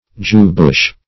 Jewbush \Jew"bush`\, n. (Bot.)